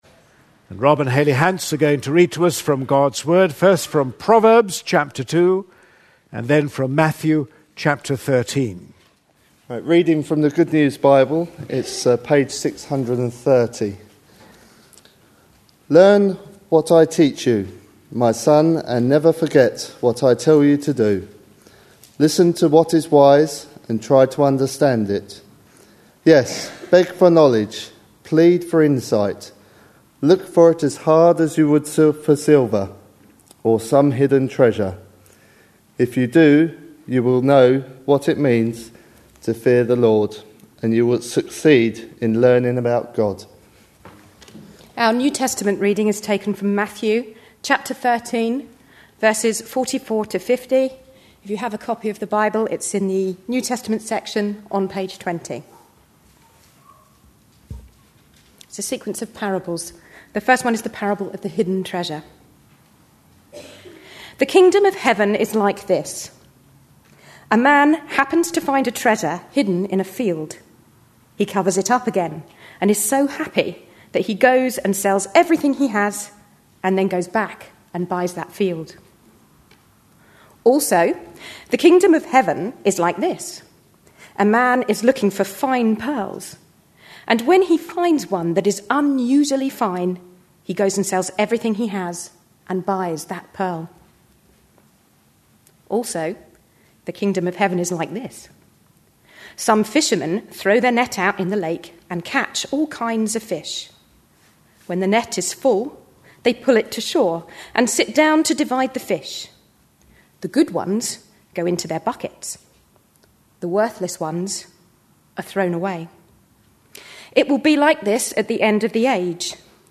A sermon preached on 5th December, 2010, as part of our Parables of Matthew series.